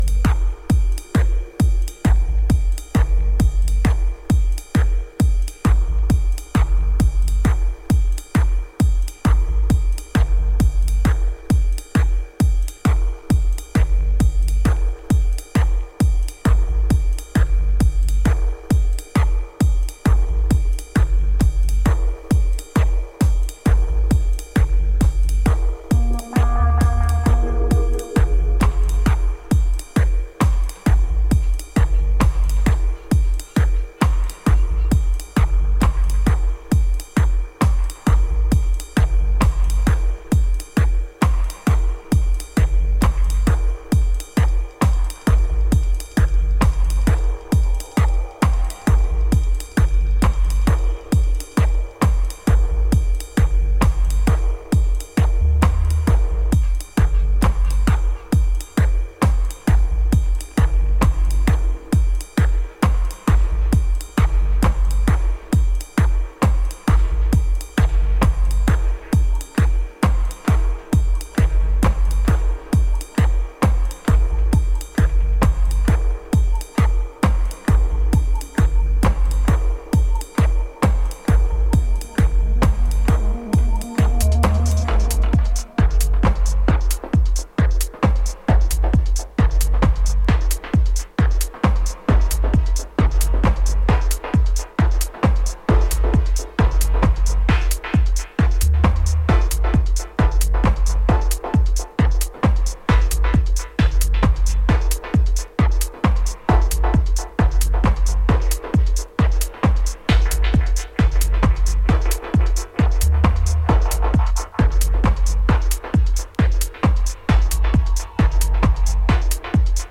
ストイックに打ち込まれる攻撃的ステッパービートとオルガン系リフの飛ばしにトランス必至の